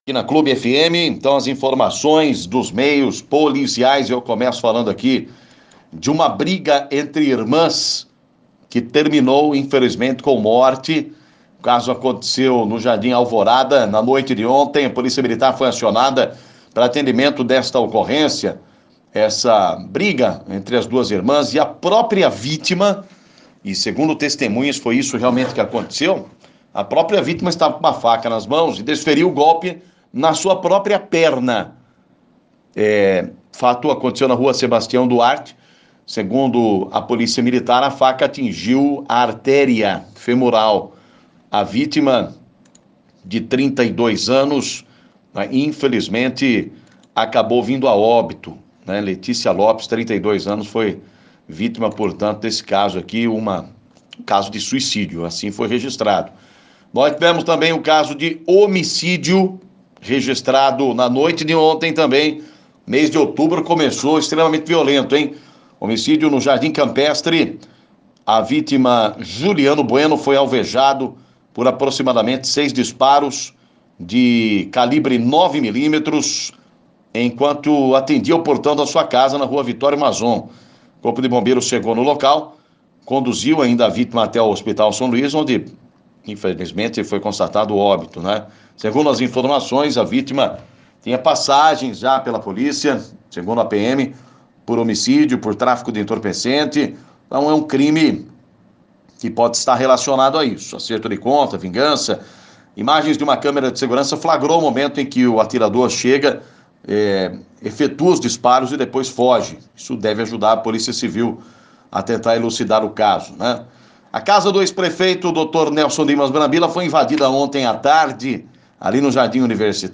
Polícia